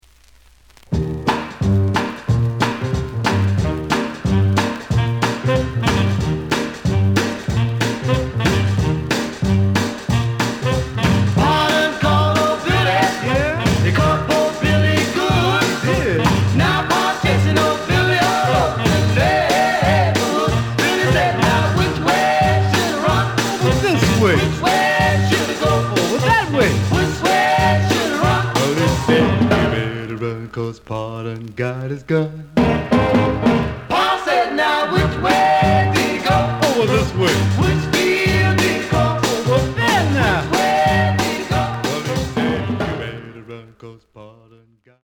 The listen sample is recorded from the actual item.
●Genre: Rhythm And Blues / Rock 'n' Roll